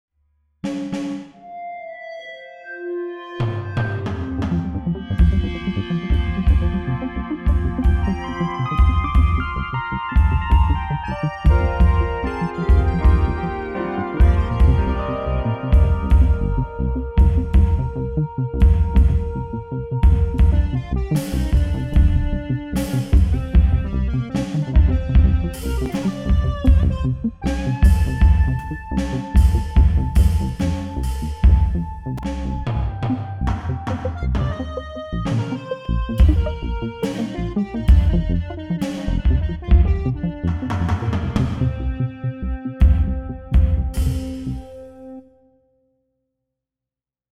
Made with Free and Open Source Software analog synthesizer simulation, and LADSPA plugins on Linux (no windows was used at all for this music) this piece of music (not crap) which is just testing some possibilities features analog synth sounds with effects with actual synth sounds, not some prefab samples.
(1.44 MB, the same music but ´treated´ with Jamin, in mp3)
The last version has been fed through Jamin, the 32 band equalizer program for Linux, with mulitband compression and loudifier. Doesn´t sound better in my opinion, but some people like it.